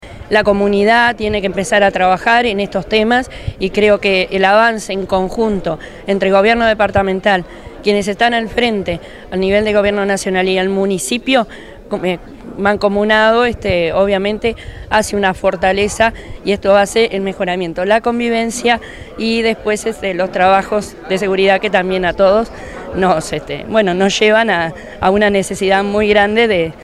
En marco del cierre de la Semana de la Convivencia, se realizó en Mercado Arenas de Carrasco (Municipio de Paso Carrasco) un conversatorio sobre la convivencia y rehabilitación como desafíos de Estado.
Finalmente, la alcaldesa de Paso Carrasco, Verónica Veiga, habló de la receptividad que tuvo llevar adelante el cierre de la semana en el Municipio; 'La comunidad tiene que empezar a hablar estos temas", sostuvo.